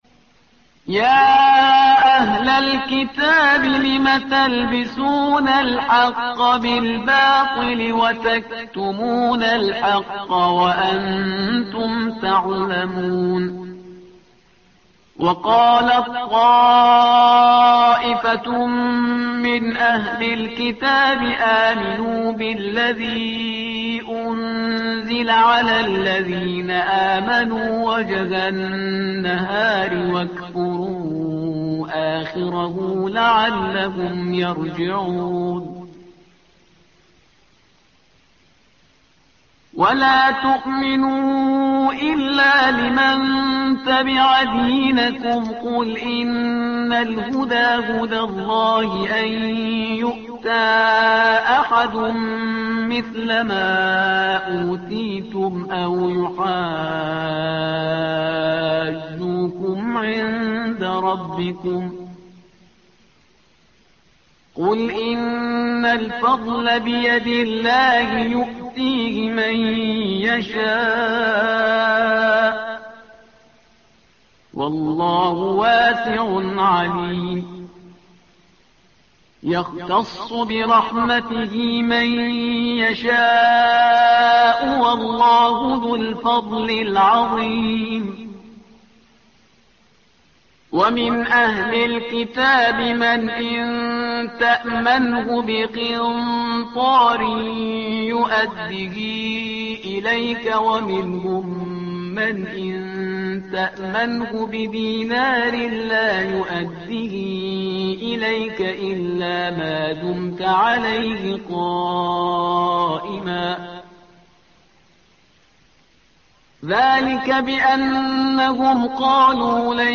تحميل : الصفحة رقم 59 / القارئ شهريار برهيزكار / القرآن الكريم / موقع يا حسين